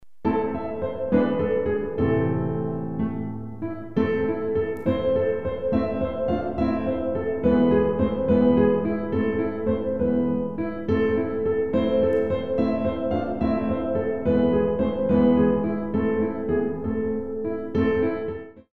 Pt. saut